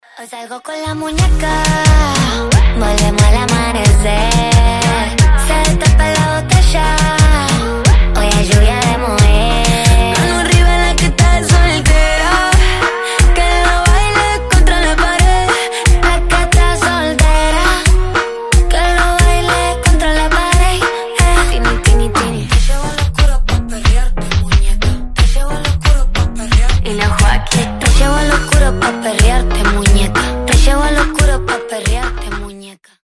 • Качество: 320, Stereo
ритмичные
женский вокал
заводные
латиноамериканские
Reggaeton